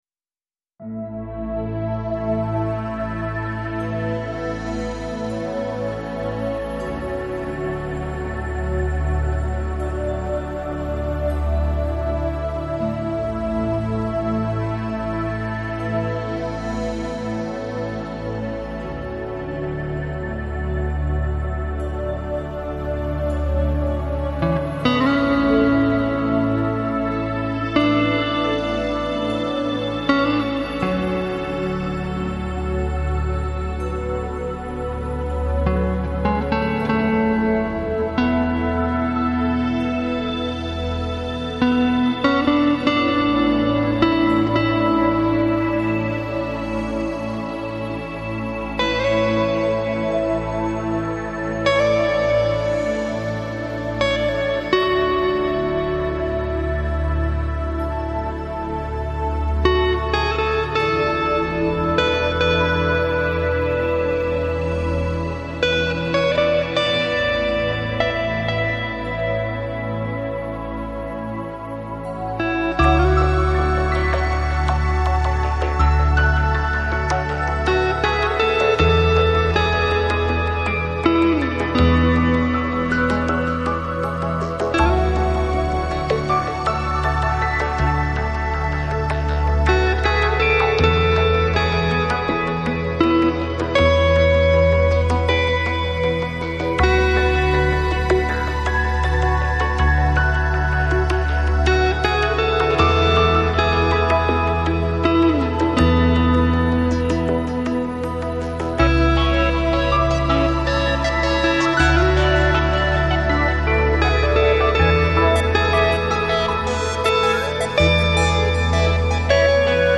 Жанр: New Age, Relax, Ambient